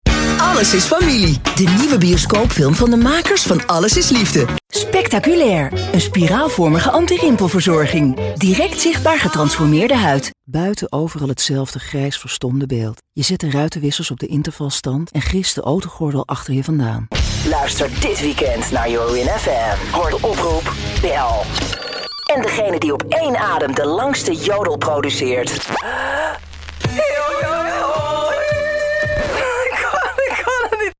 warm, enthousiastic, edgy, tongue and cheek, fresh, sexy, emphatic, strong, raw, young
Sprechprobe: Werbung (Muttersprache):